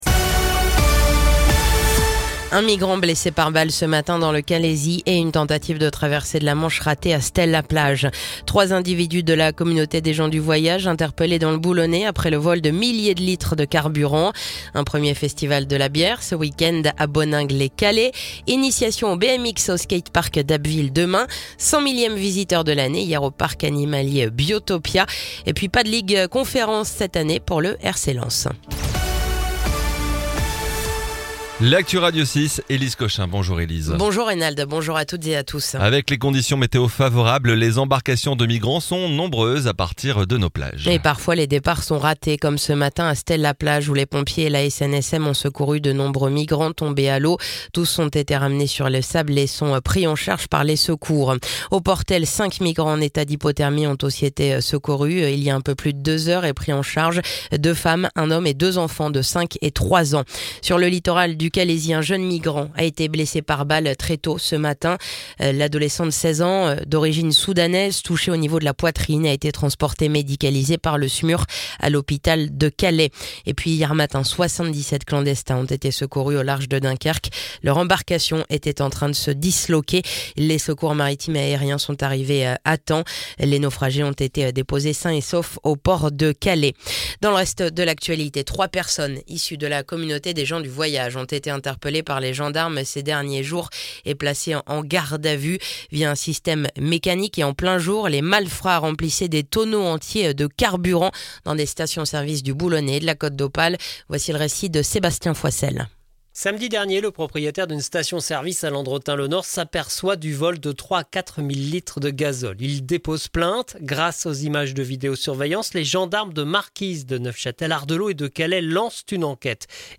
(journal de 9h)